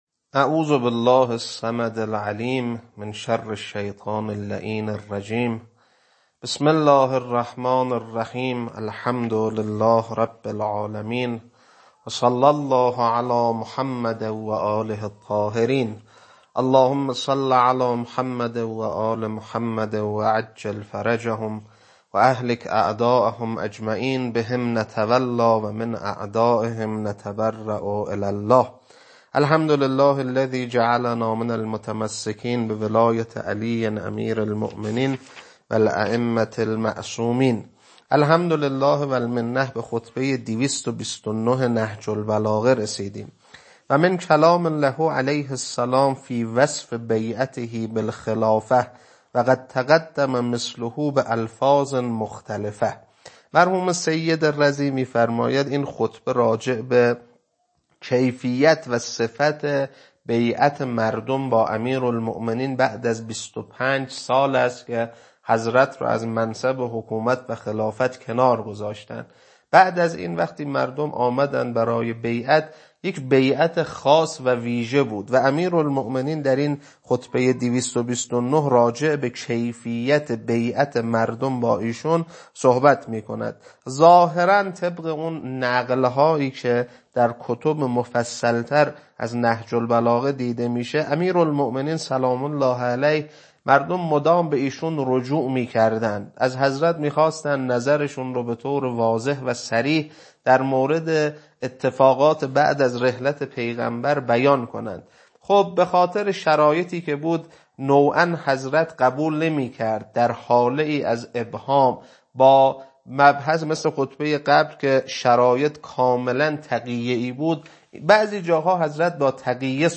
خطبه-229.mp3